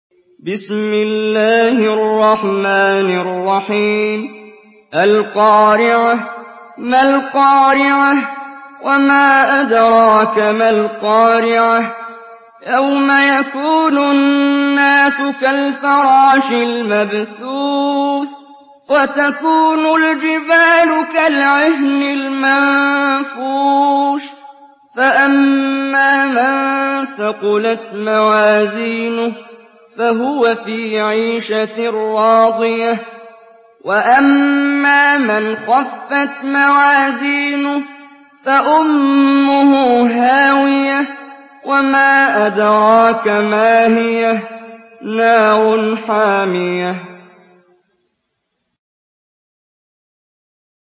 Surah আল-ক্বারি‘আহ্ MP3 by Muhammad Jibreel in Hafs An Asim narration.
Murattal Hafs An Asim